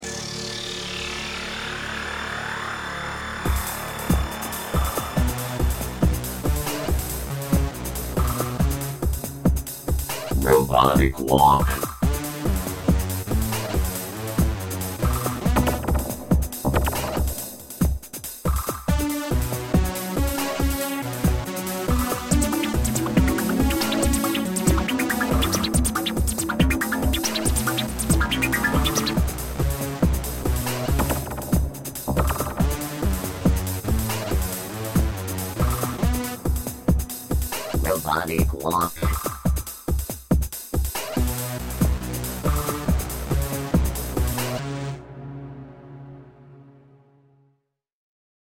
TRANSTECHNO